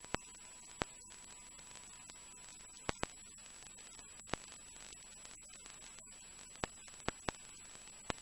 随机频率调制（混响）2
描述：两个随机频率调制（混响）的前后例子。
标签： 频率 火星 调制 随机的 随机频率调制
声道立体声